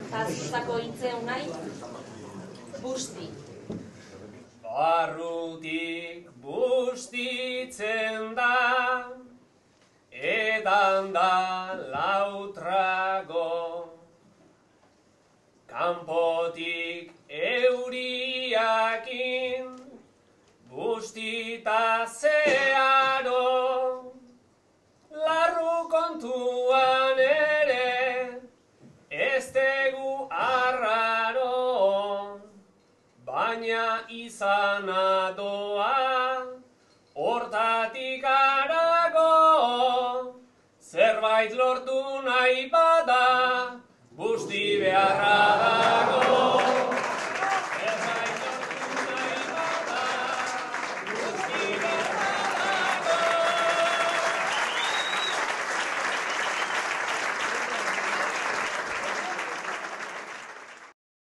Osinalde Saria, afaria